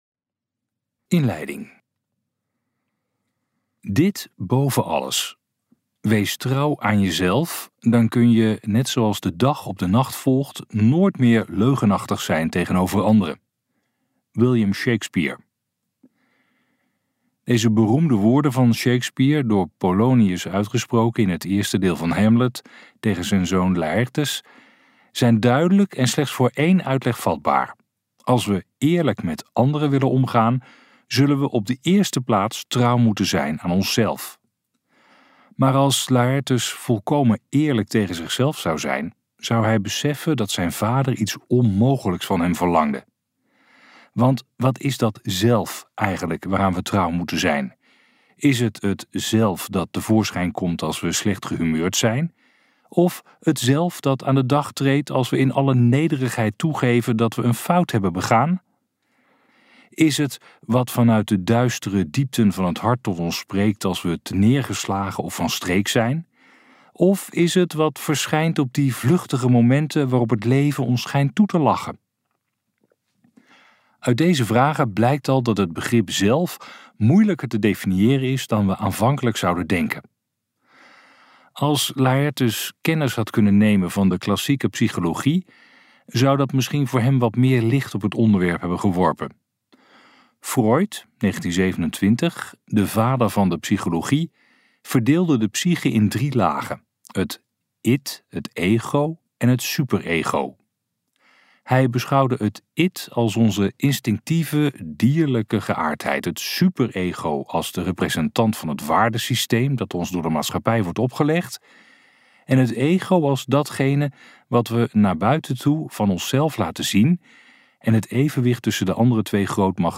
Uitgeverij Ten Have | Geluk zonder voorwaarden luisterboek